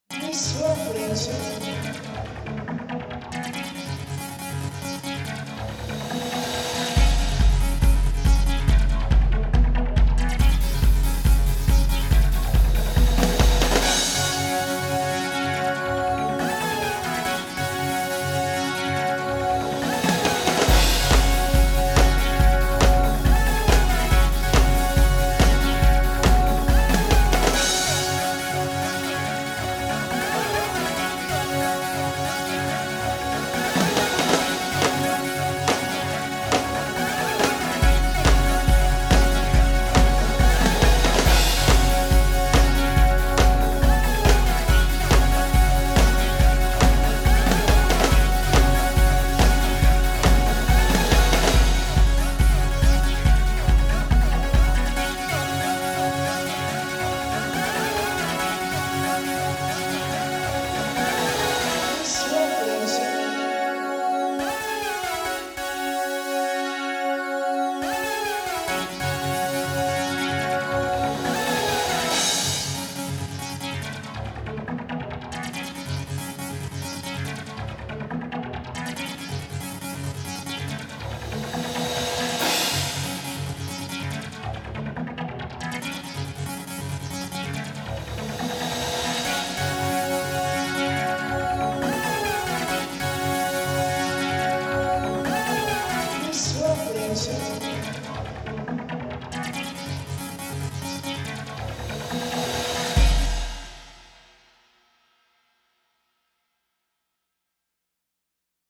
A friend asked me to come up with an upbeat little tune for the Laserdisc game Space Pirates.